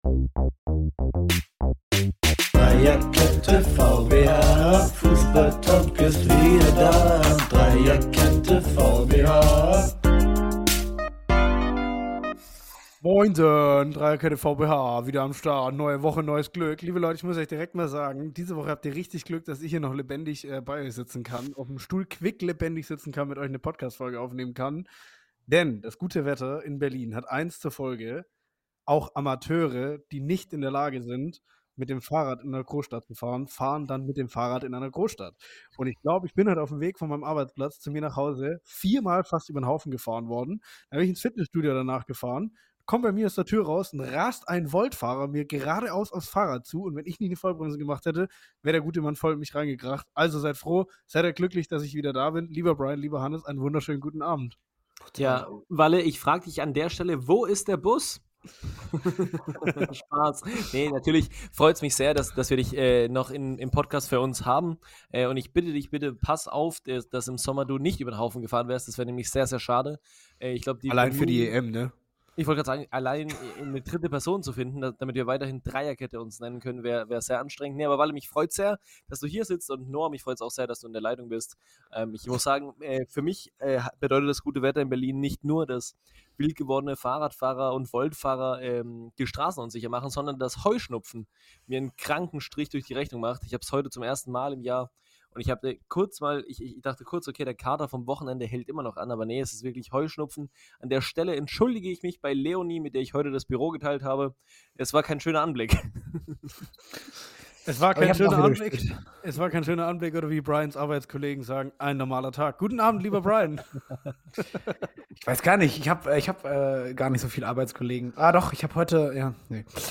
Folgen wie diese brauchen nicht viel Einführung; drei Fußballnerds reden über ihren Lieblingssport und versuchen alle mit ihrem Halbwissen zu begeistern. Champions League und Abstiegskampf full - viel Spaß beim Zuhören!